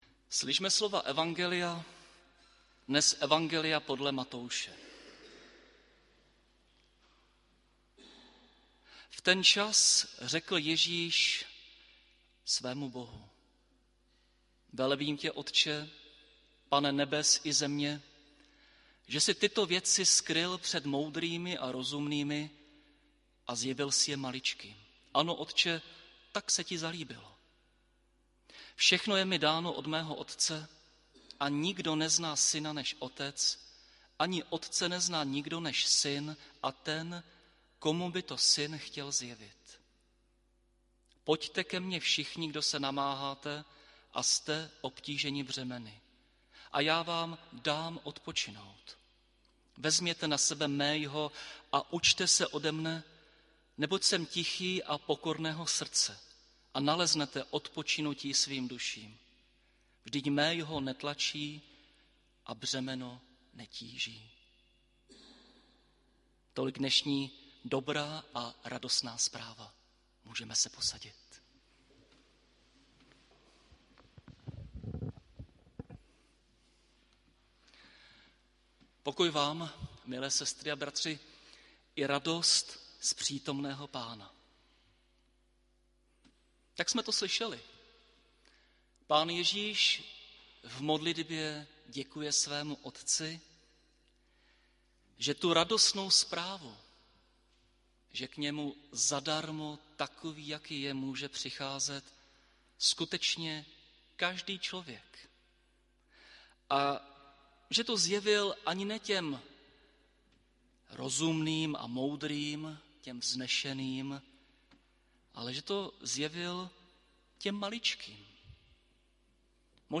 19. neděle po sv. Trojici 15. října 2023 – rodinná neděle